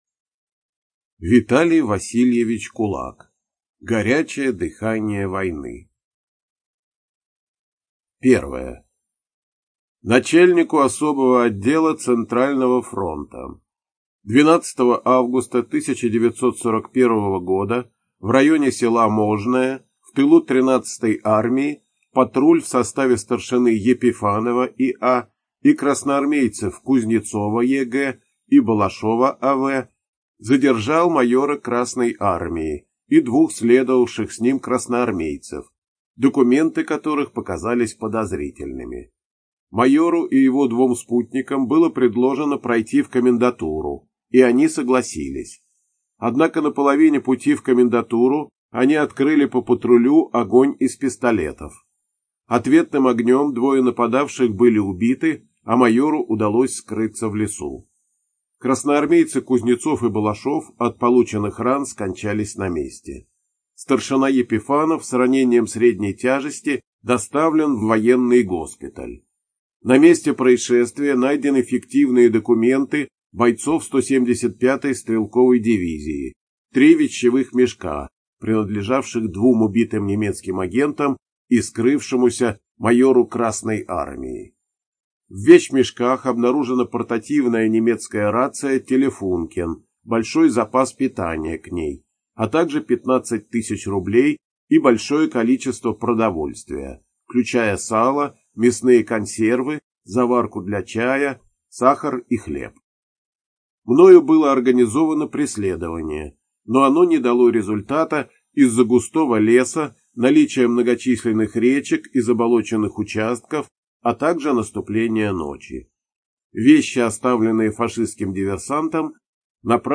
ЖанрВоенная литература